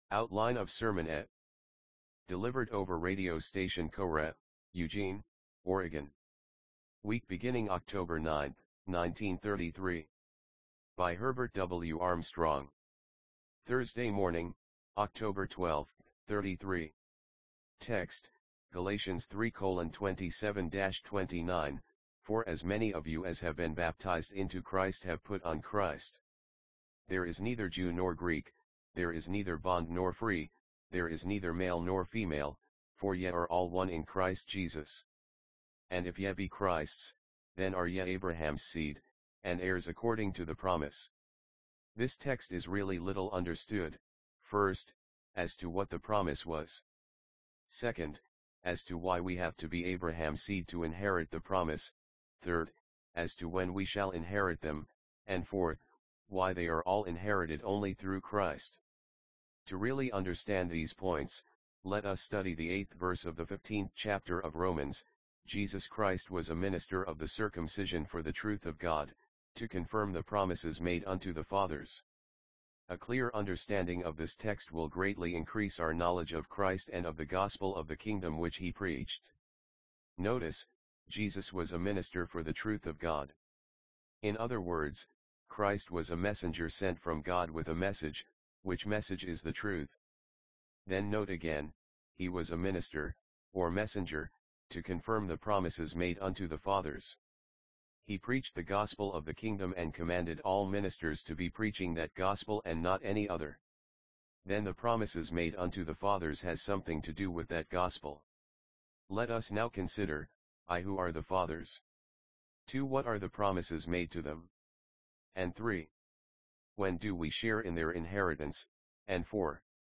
NB: a program was used to convert each of these broadcasts to MP3.